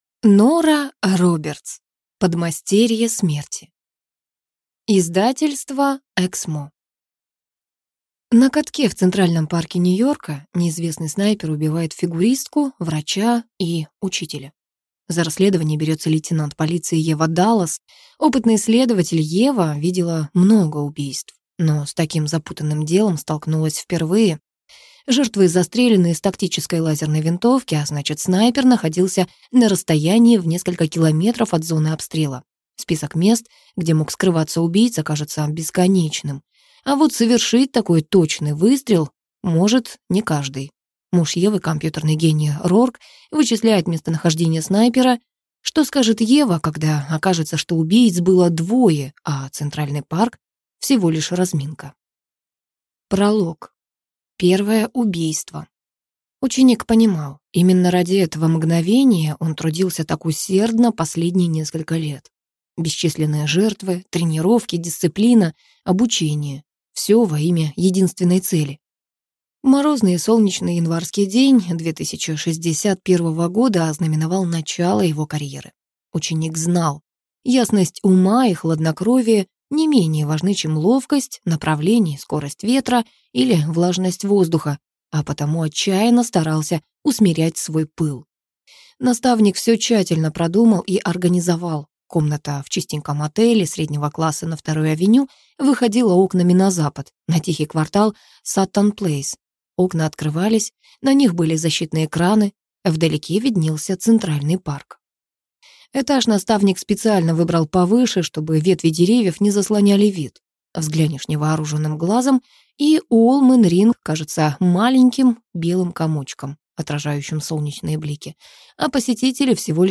Аудиокнига Подмастерье смерти - купить, скачать и слушать онлайн | КнигоПоиск